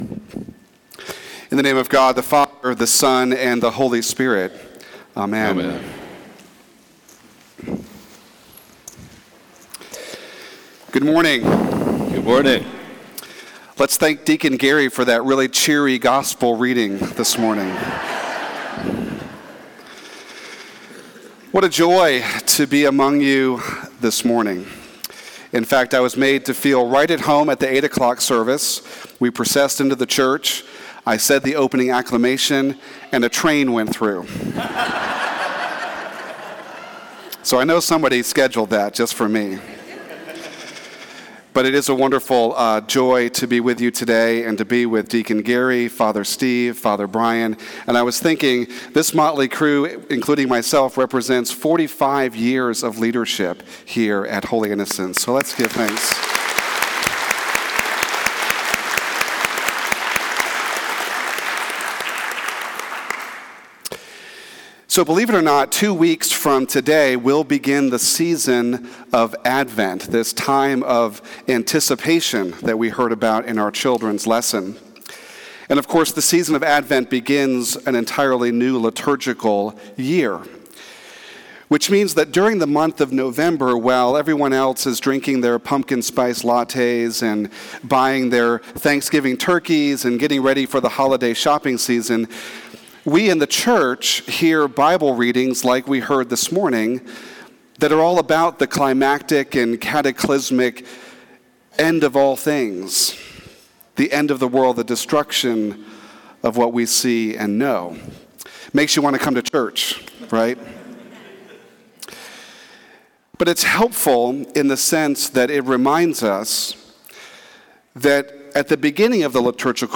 Sermon 11/13/2022 Twenty-third Sunday after Pentecost - Holy Innocents' Episcopal Church